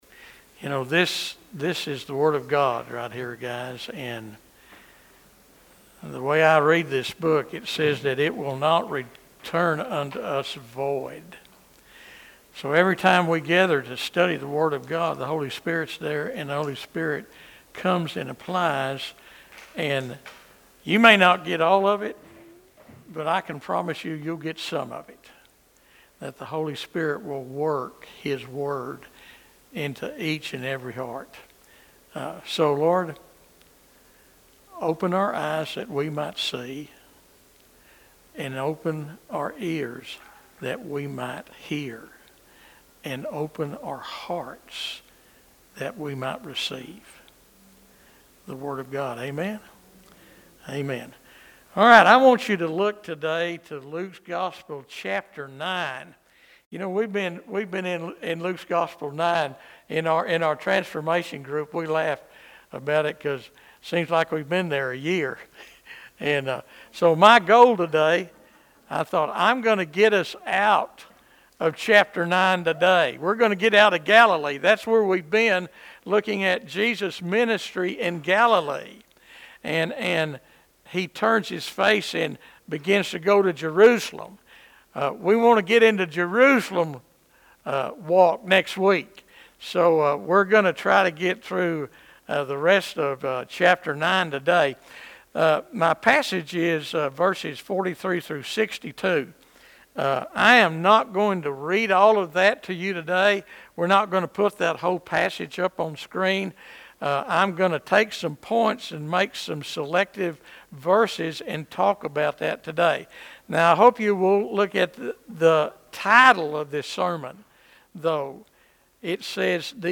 5.11.25 sermon.mp3